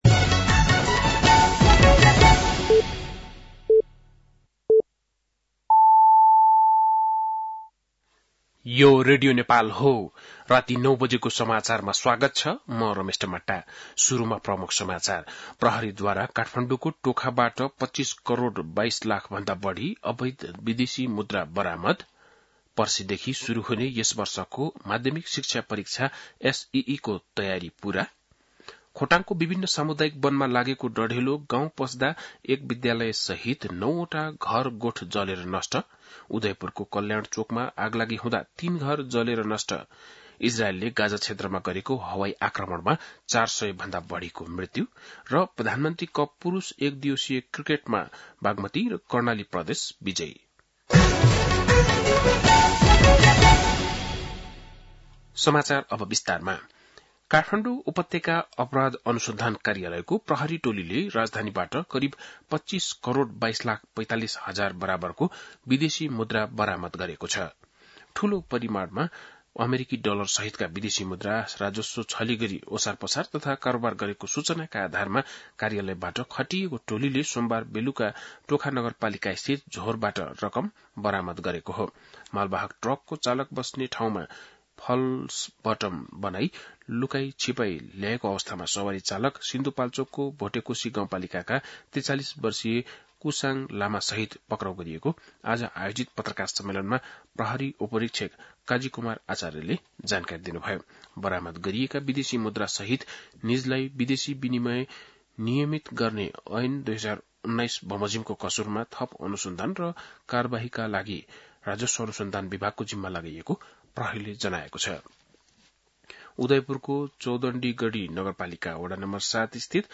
बेलुकी ९ बजेको नेपाली समाचार : ५ चैत , २०८१